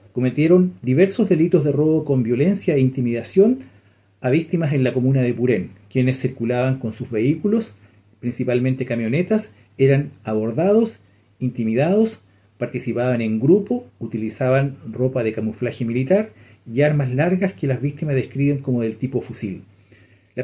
El fiscal Marco Pavez, encargado de indagar este caso, habló de cómo operaba este grupo armado.